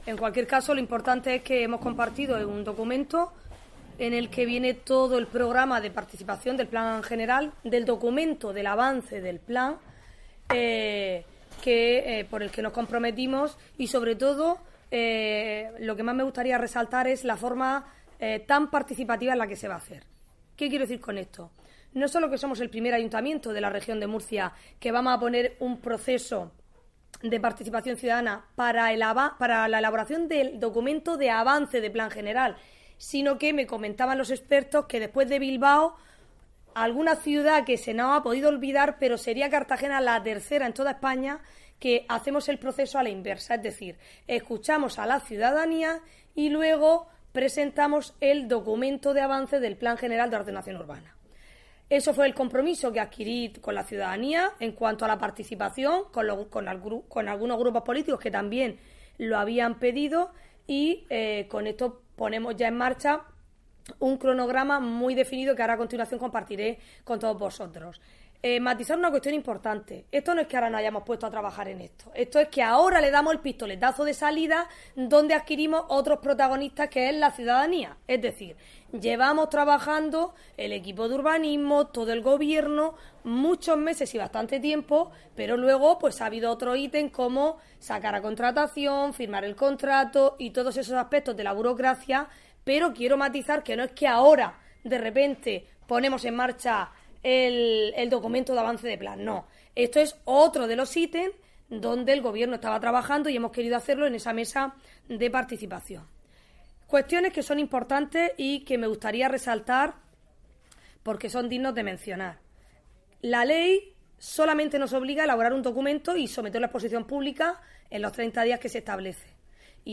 Audio: Comparecencia de la alcaldesa sobre el avance del Plan General de Ordenaci�n Urban�stica (MP3 - 10,47 MB)